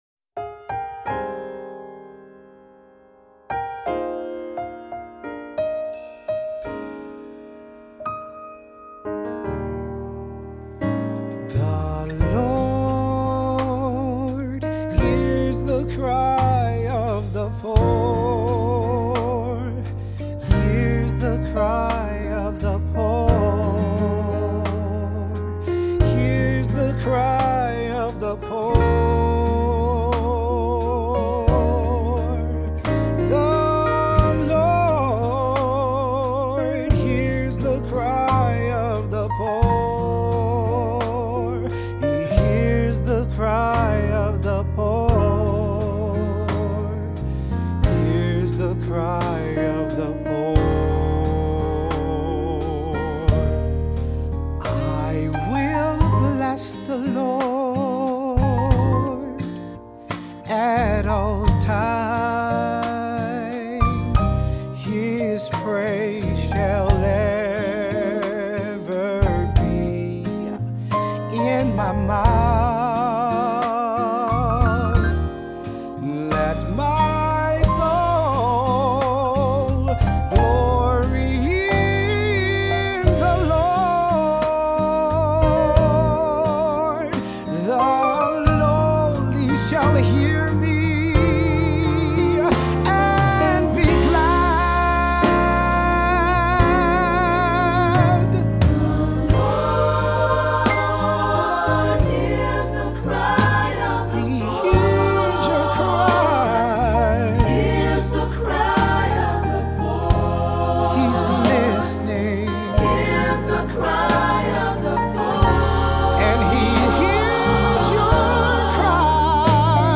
Voicing: SAT; Cantor; Assembly